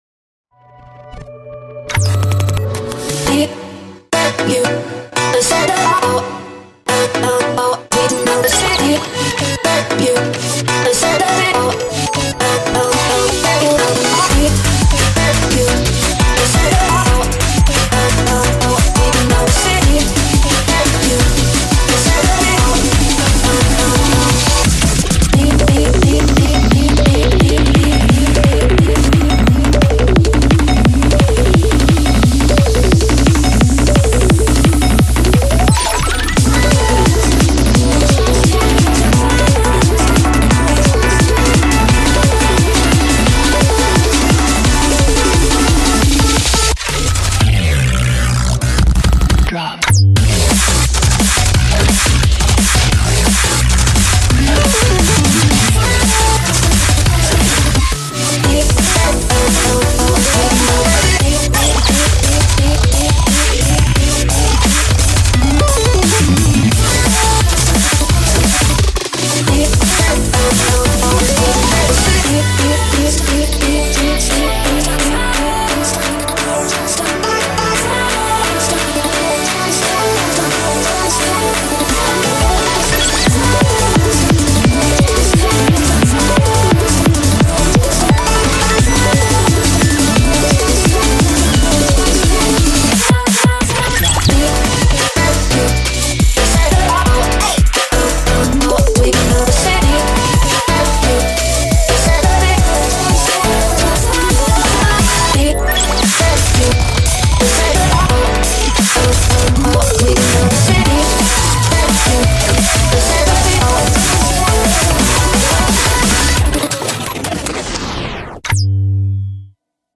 BPM87-348
Audio QualityPerfect (Low Quality)